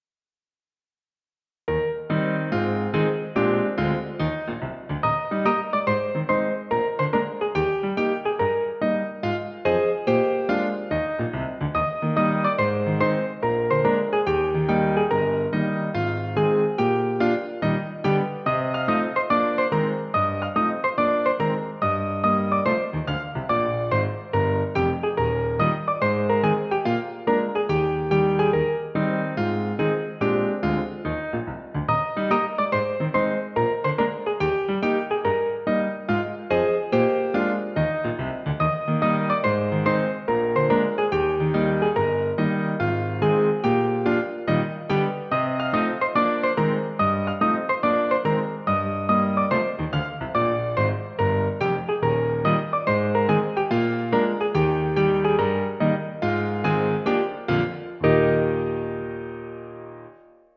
Piano accompaniment
Musical Period 19th century British, Australian, American
Tempo 143
Rhythm March
Meter 4/4